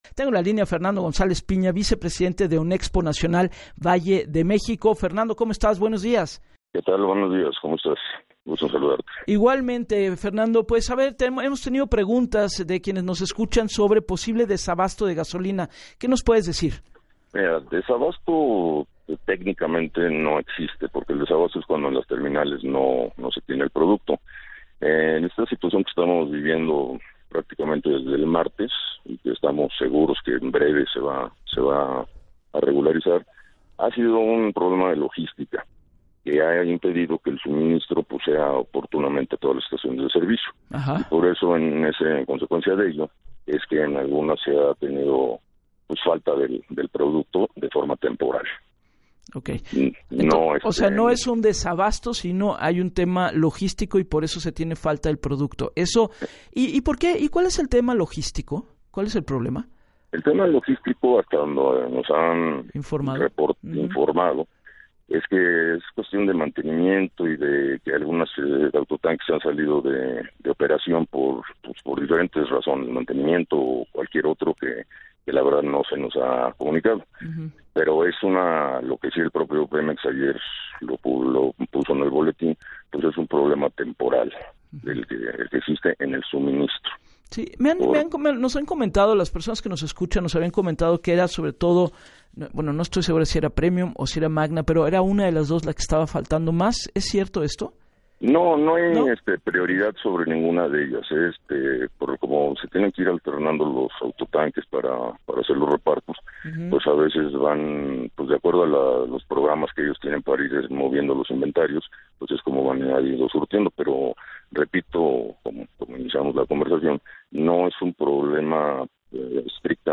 En entrevista para Así las Cosas con Gabriela Warkentin